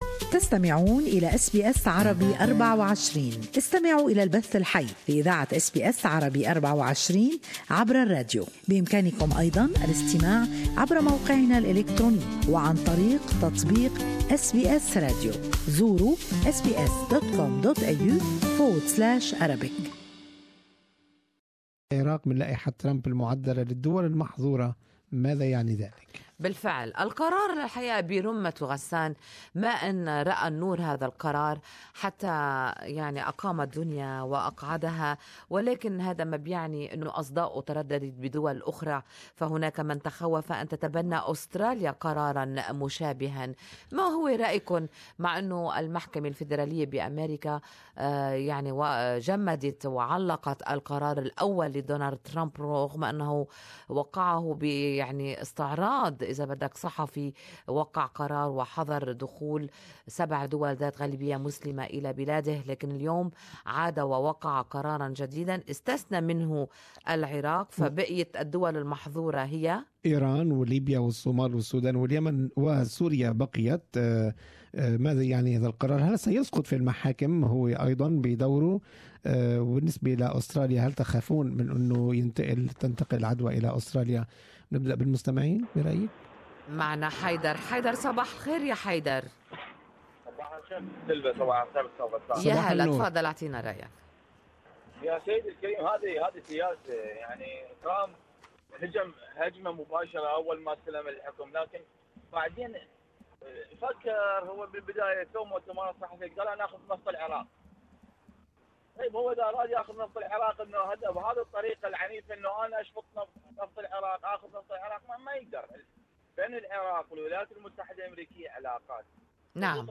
Good Morning Australia listeners share their opinions on this topic.